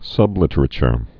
(sŭblĭtər-chr, -chər)